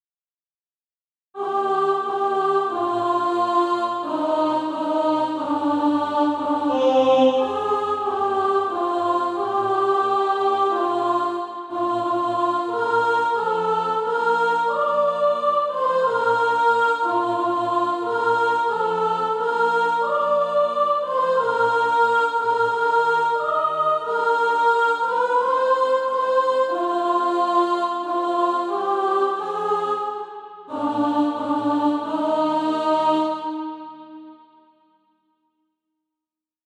Soprano Track.
Practice then with the Chord quietly in the background.